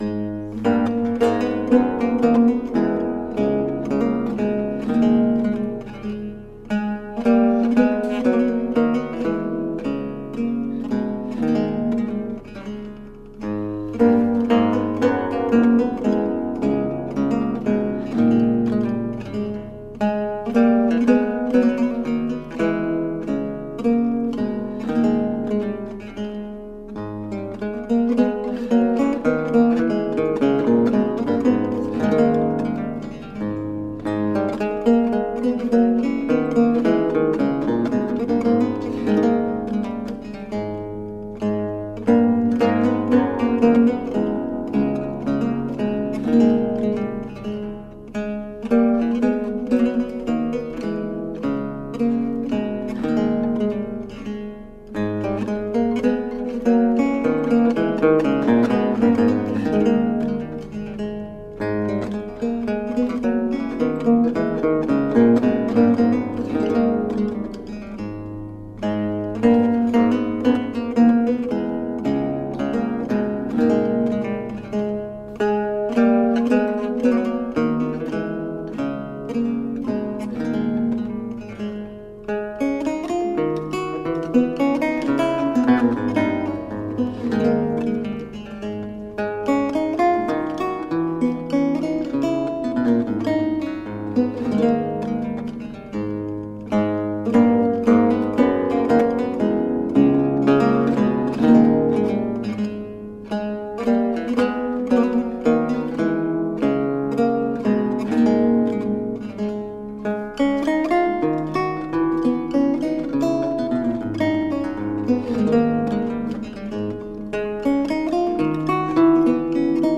Ecouter une chaconne jouée au luth